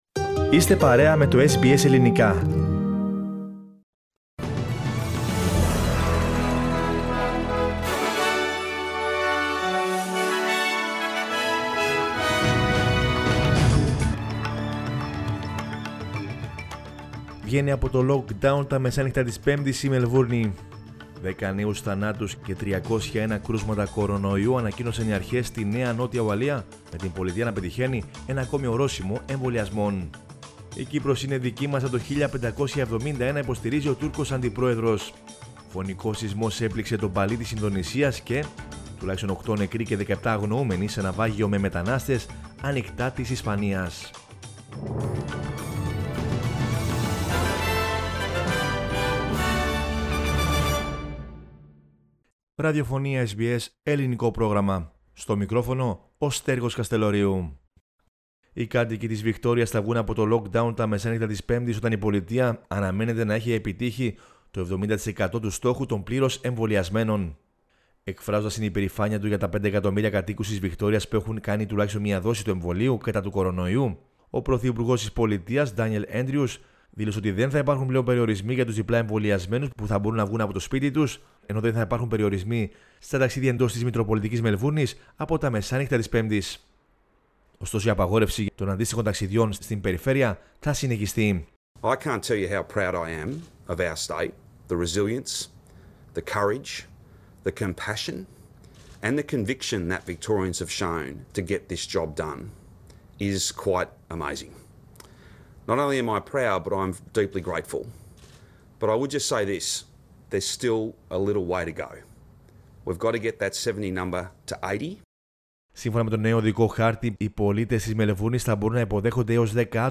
News in Greek from Australia, Greece, Cyprus and the world is the news bulletin of Sunday 17 October 2021.